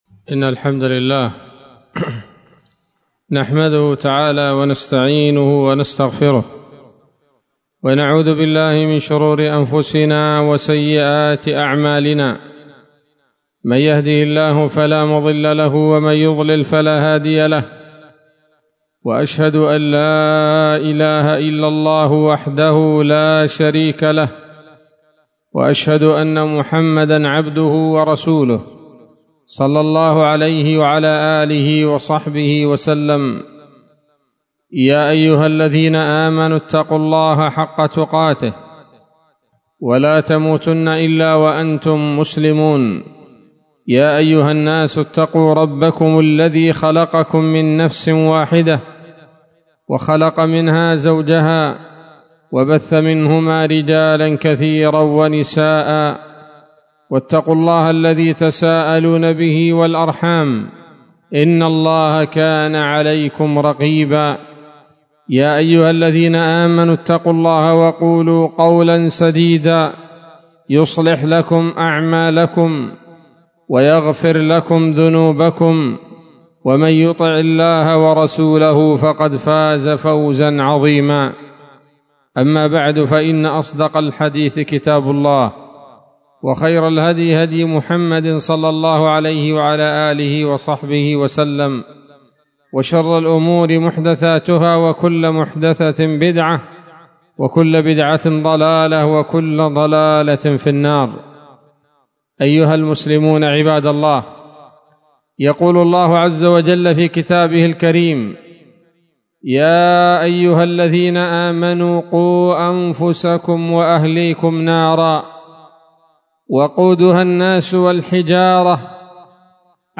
خطبة جمعة بعنوان: (( أبواب النار وطبقاتها وسرادقها )) 16 ذو الحجة 1443 هـ، دار الحديث السلفية بصلاح الدين